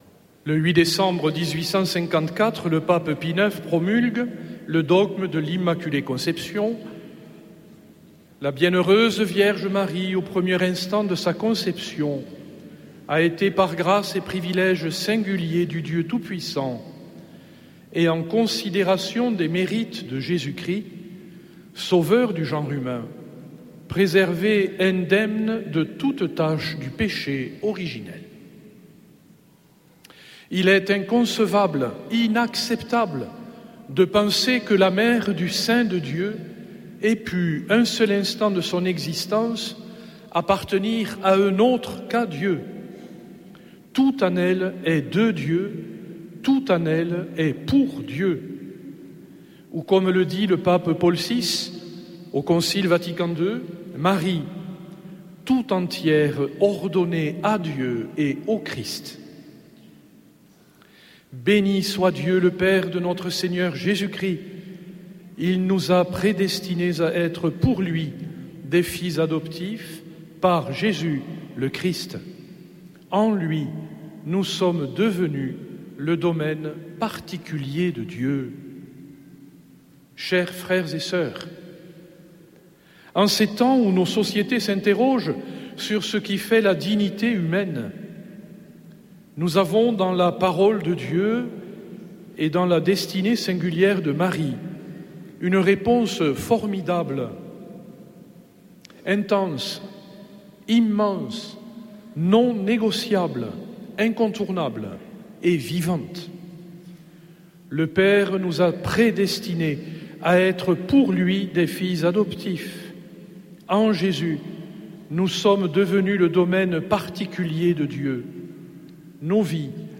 dimanche 8 décembre 2024 Messe depuis le couvent des Dominicains de Toulouse Durée 01 h 30 min